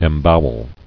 [em·bow·el]